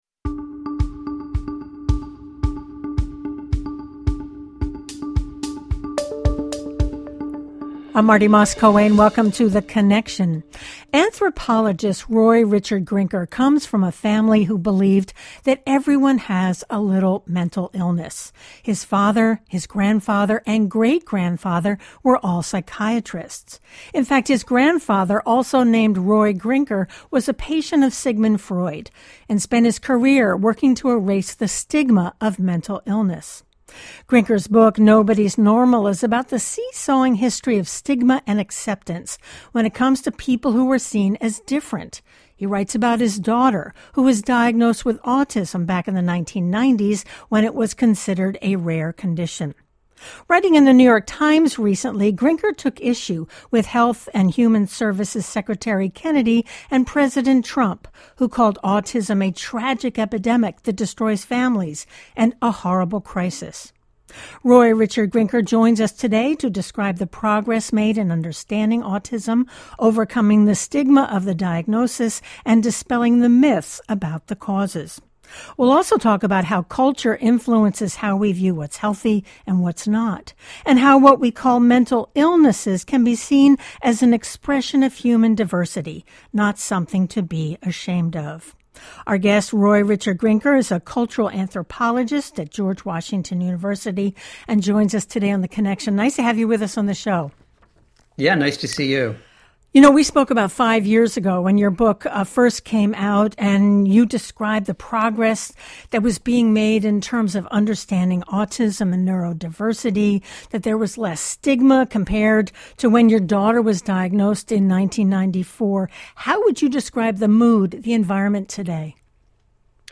In “Raising Lazarus” she introduces audiences to the people on the frontlines of the opioid crisis, who are trying to save lives — one person at a time. We talk to Macy about her book and hear from people who are trying new ways to keep people alive and get them into treatment.